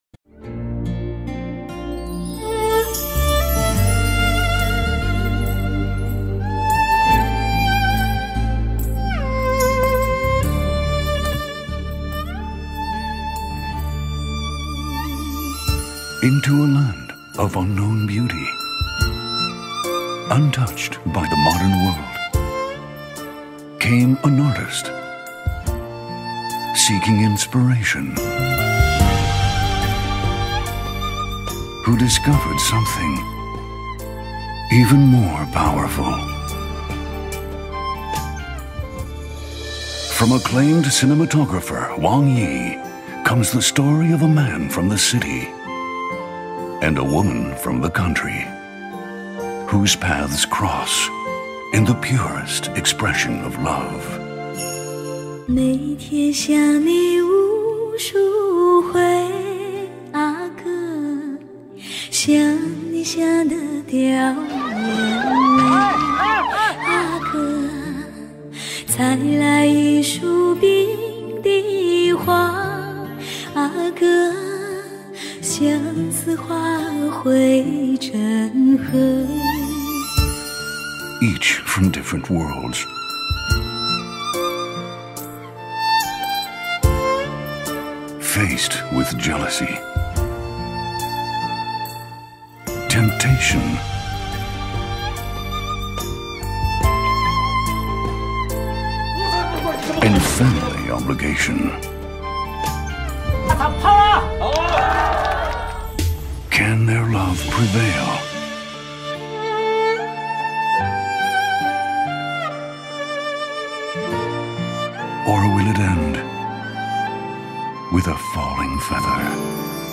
English inflection: Neutral North American
Tone: Baritone